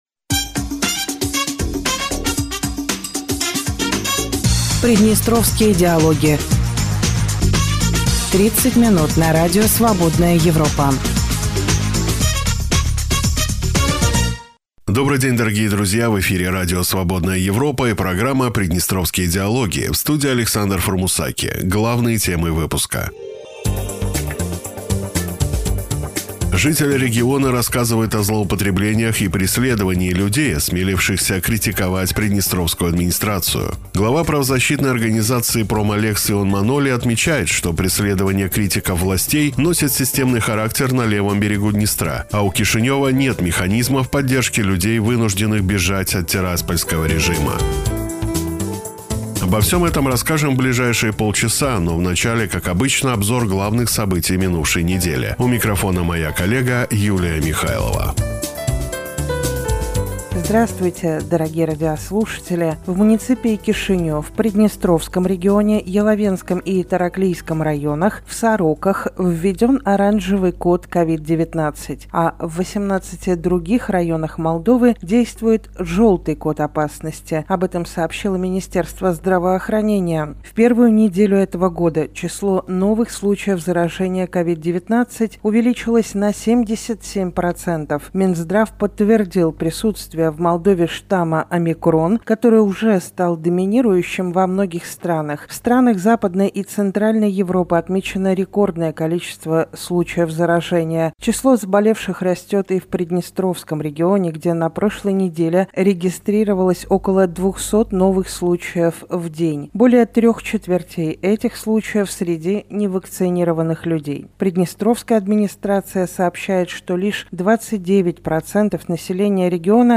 В эфире Радио Свободная Европа и программа Приднестровские диалоги.